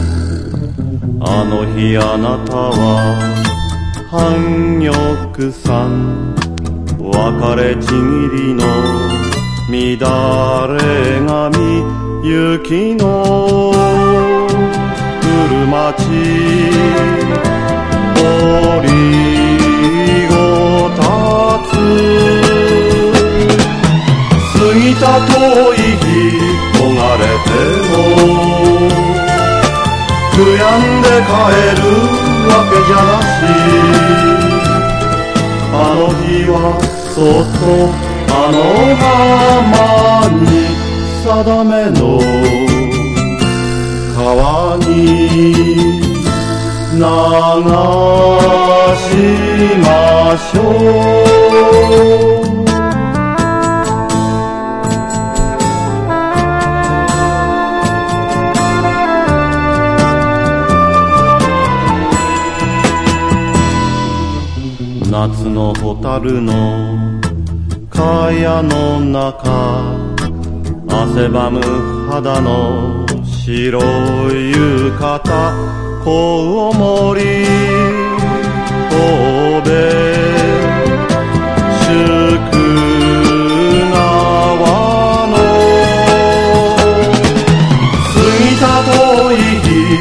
昭和歌謡をベースにジャズやプログレ等の要素も感じさせるブラックユーモア満載の詞世界が描かれます。